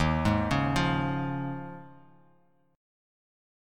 D#7sus2sus4 Chord
Listen to D#7sus2sus4 strummed